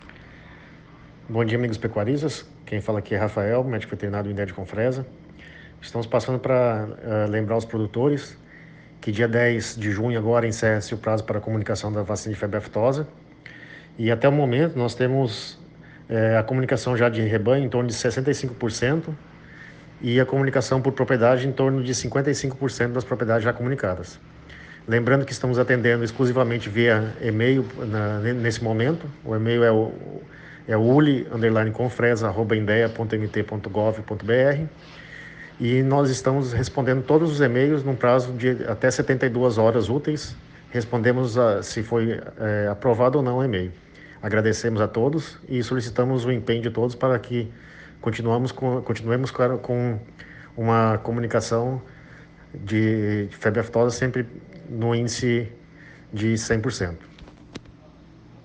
Em entrevista exclusiva ao site Repórter Agro